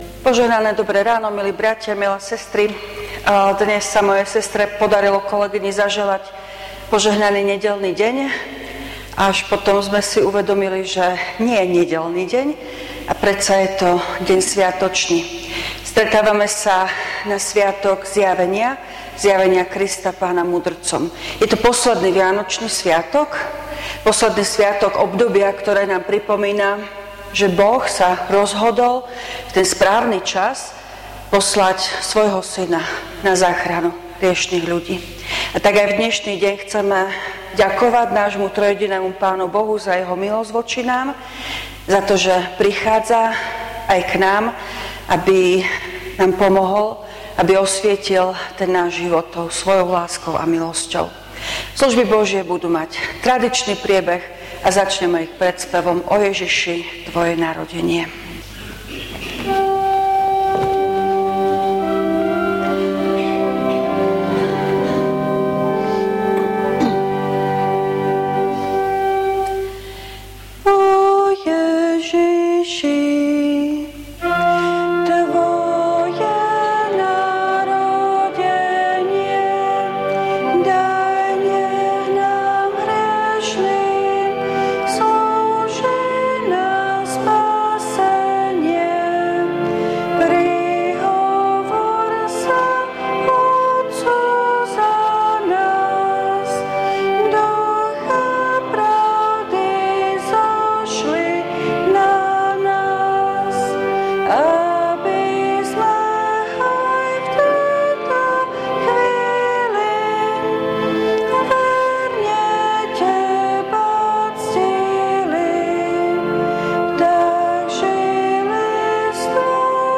V nasledovnom článku si môžete vypočuť zvukový záznam zo služieb Božích – Zjavenie Krista Pána mudrcom.